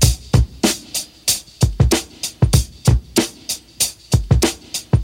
• 95 Bpm Drum Loop C Key.wav
Free drum beat - kick tuned to the C note. Loudest frequency: 2628Hz
95-bpm-drum-loop-c-key-jMR.wav